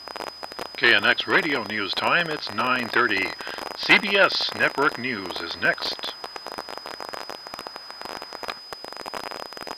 I think I got most of the “normal” distortions down for a standard, “fringe area” broadcast.
So that almost has to be a transistor radio, right?